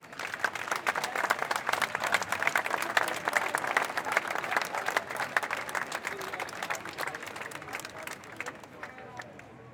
sfx_awkward_clap.ogg